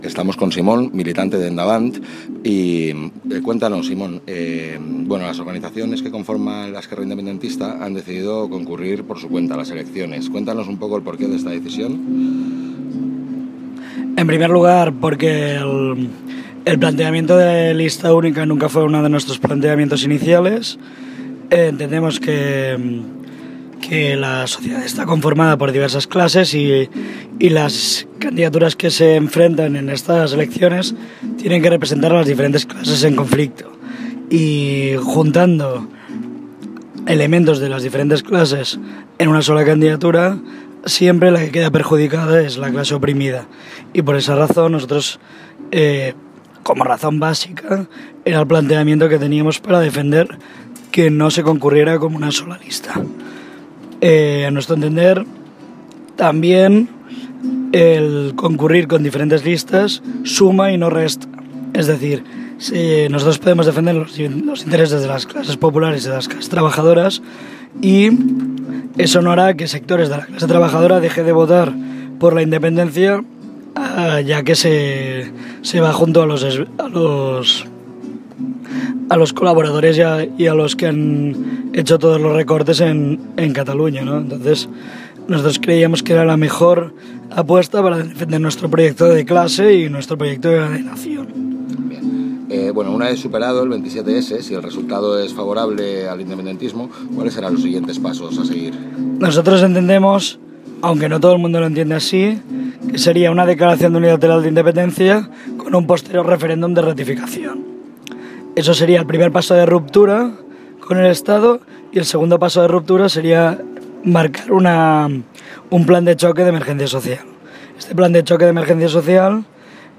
La Haine entrevista